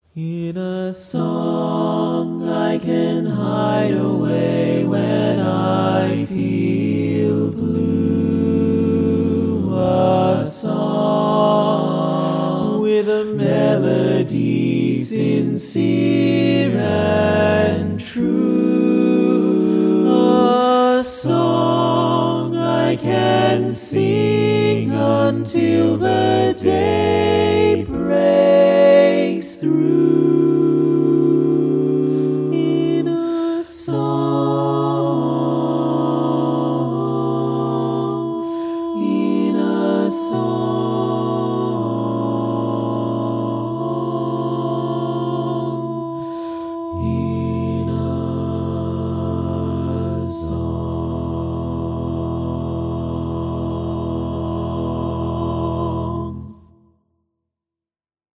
Key written in: E Major
How many parts: 4
Type: Barbershop
All Parts mix:
Learning tracks sung by